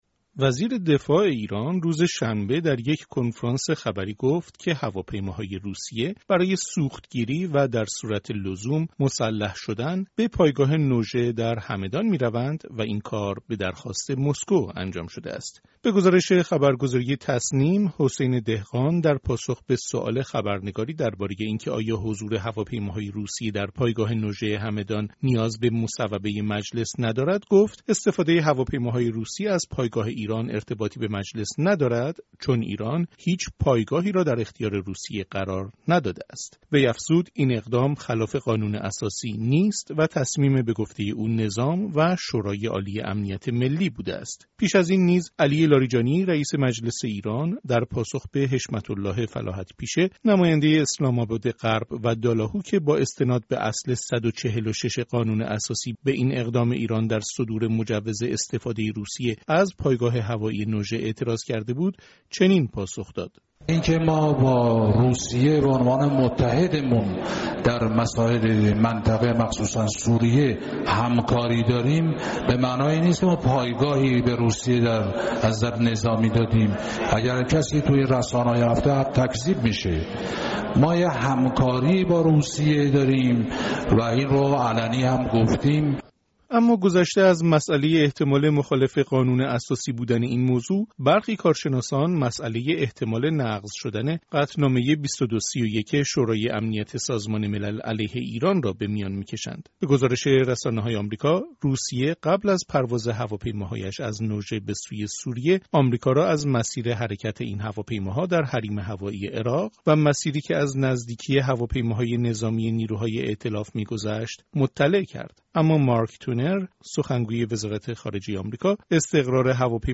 از رادیو فردا